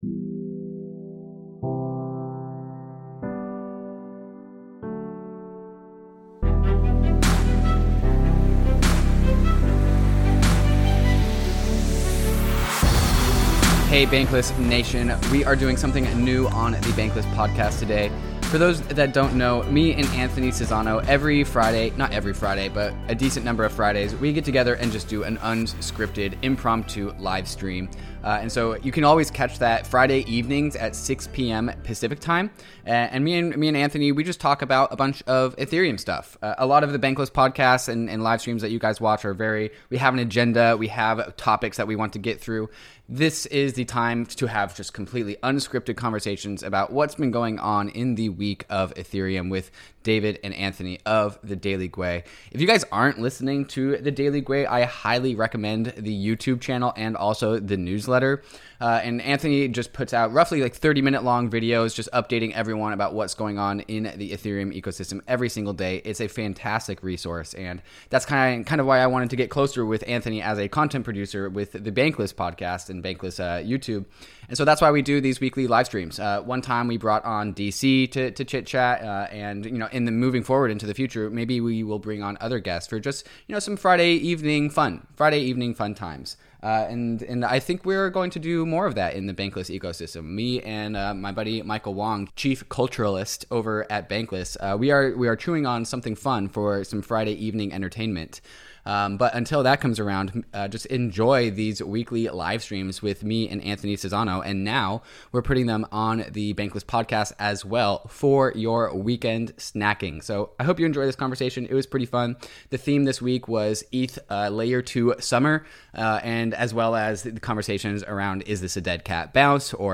Enjoy this unscripted conversation!